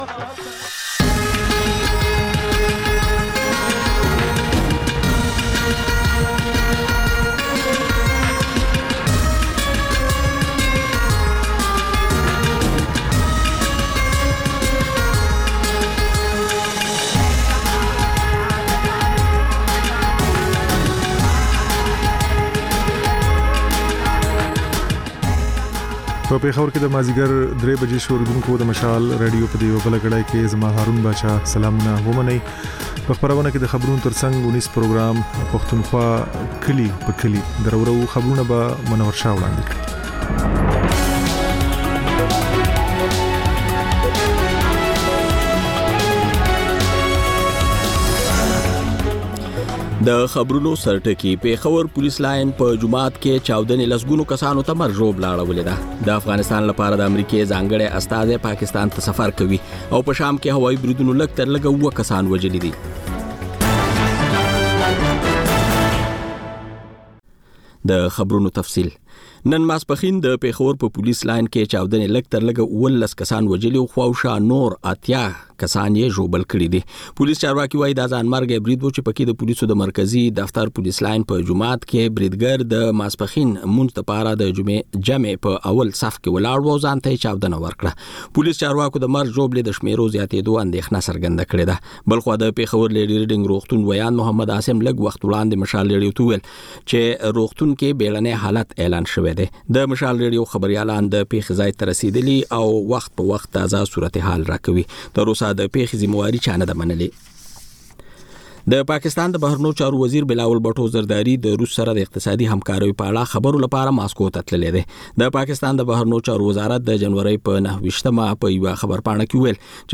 د مشال راډیو درېیمه یو ساعته ماسپښینۍ خپرونه. تر خبرونو وروسته، رپورټونه، شننې، او رسنیو ته کتنې خپرېږي.